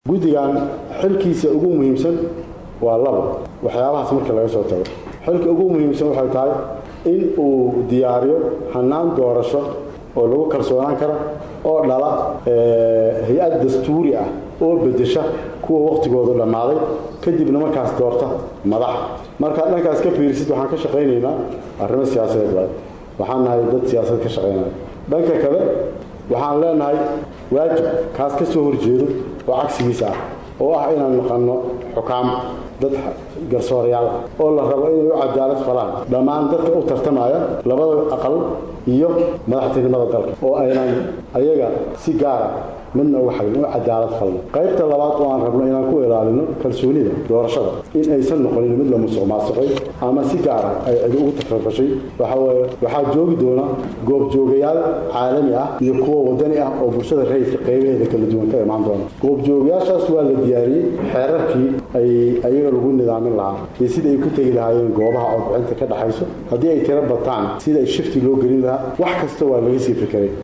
Abuukaate Cumar Maxamed Cabdulle (Dhegey) oo ah Gudoomiyaha Guddiga Doorashooyinka dadban ee Dalka oo qaar ka mid ah dadweynaha degan gudaha Dalka Kenya kula hadlayay Magaalada nairobi ayaa sheegay Gudigooda ain ay sameeyeen kormeerayaal caalami ah iyo waliba kuwo Soomaaliyeed.